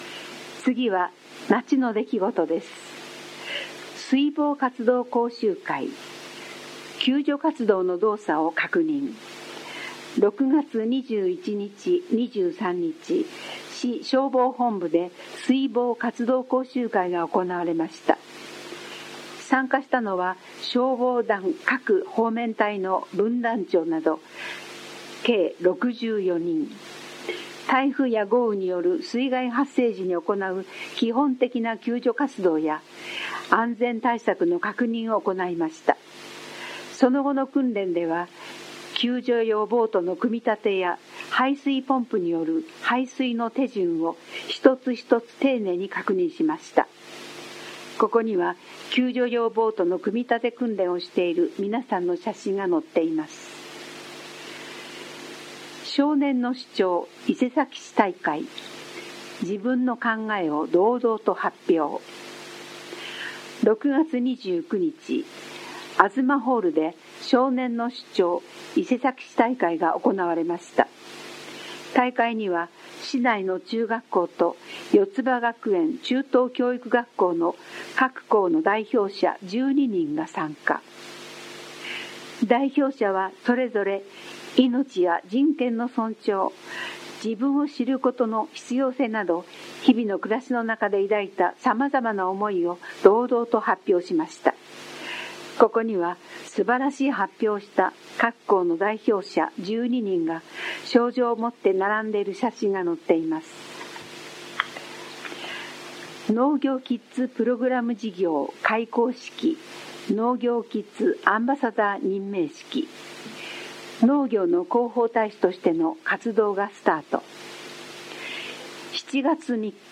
声の広報は目の不自由な人などのために、「広報いせさき」を読み上げたものです。
朗読